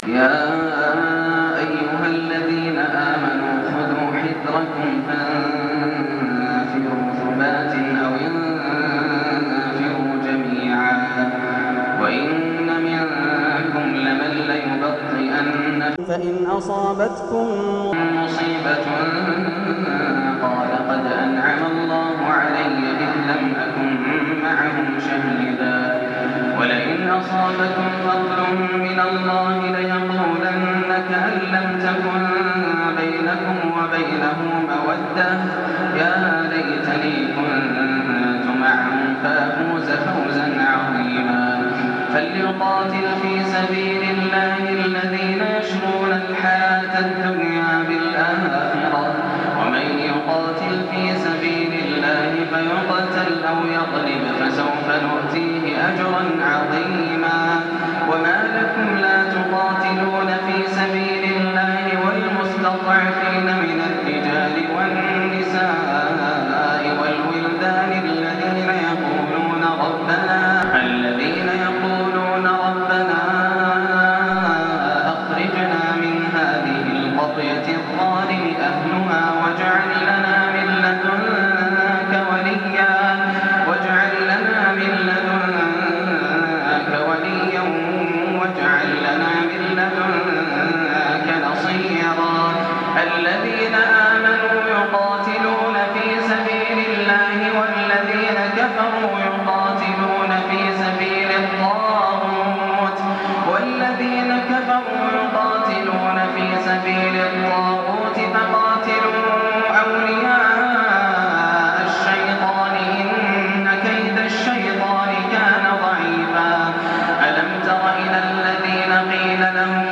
سورة النساء من آية 76 إلى نهاية السورة > رمضان 1424 هـ > التراويح - تلاوات ياسر الدوسري